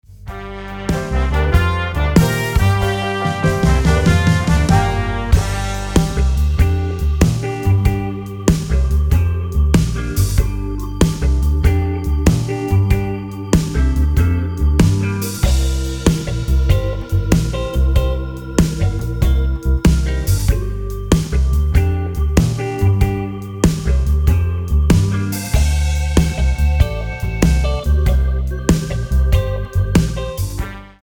95 BPM